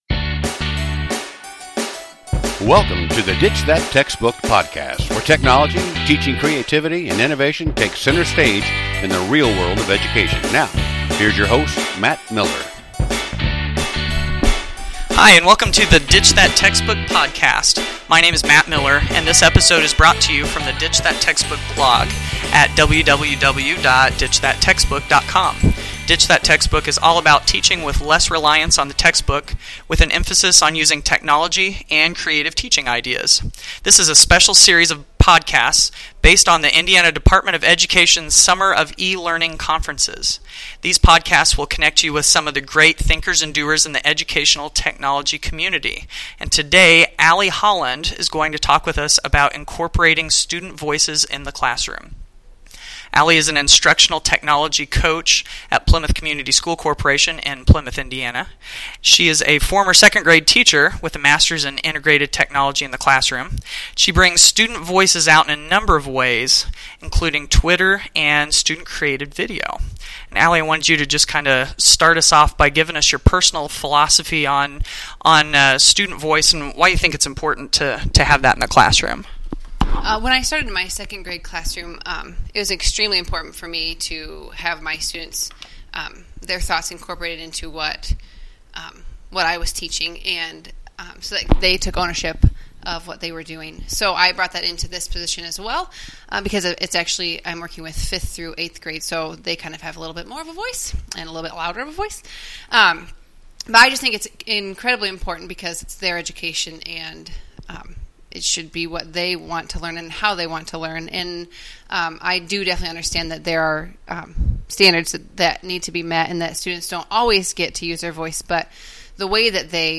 The Peace, Love and EdTech Conference at Southmont High School this week has been a thought-provoking and toolbox-building experience.